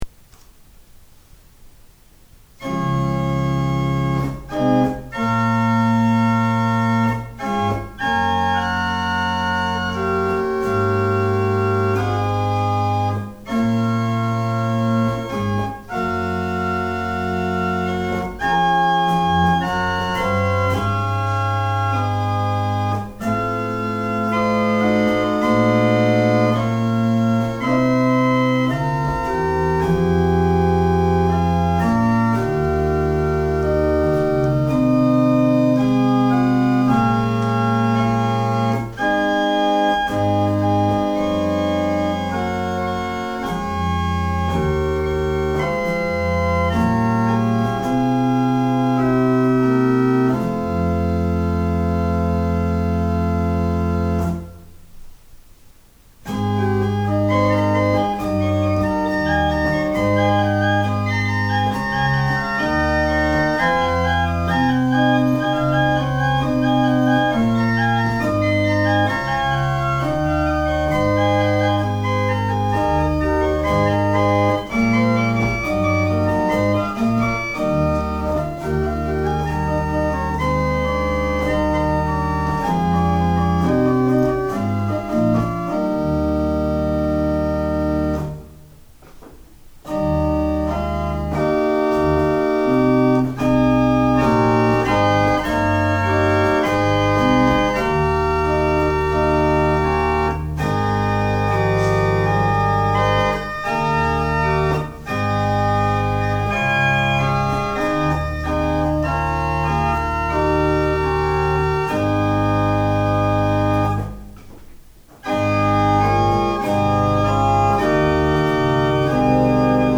Die Orgel der evangelischen Pfarrkirche Mitterbach
Klangbeispiel
Mechanische Spiel- und Registertraktur mit Schleifladen.
Track16_Plenum.mp3